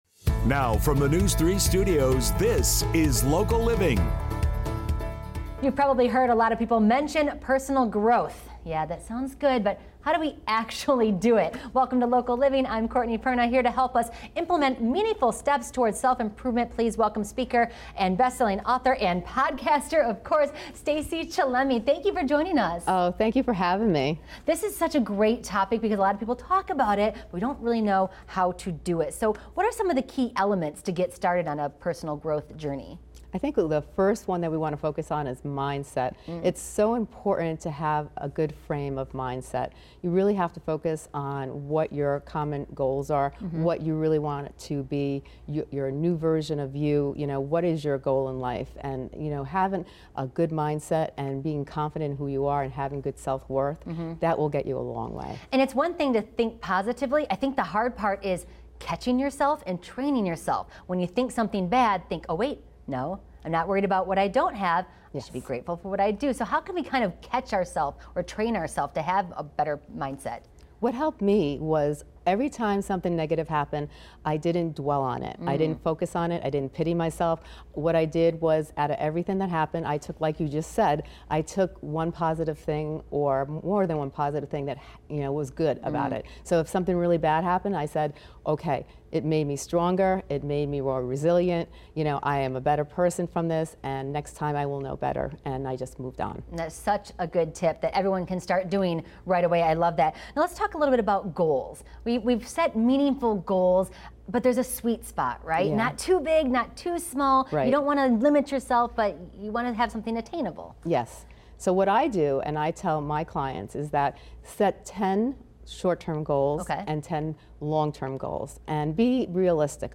Are You Stuck? Learn How to EMPOWER Yourself Now! (Interview